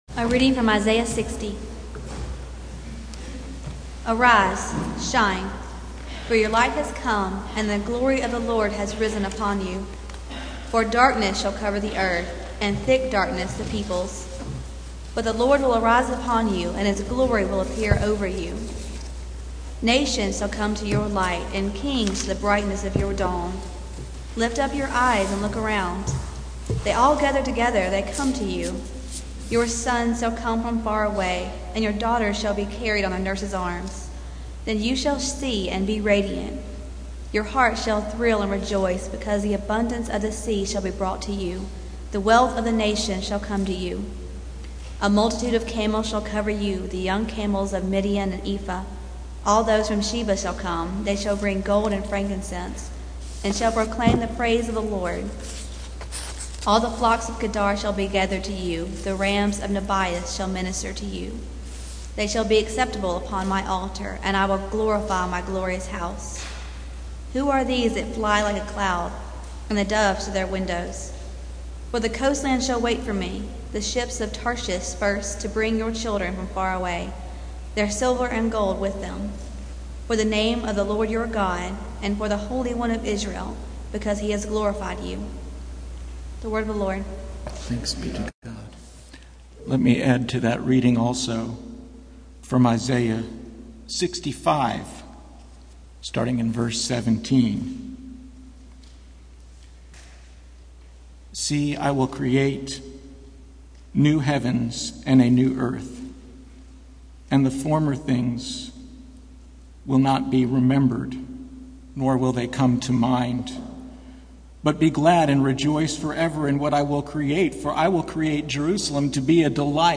Passage: Isaiah 65:17-25 Service Type: Sunday Morning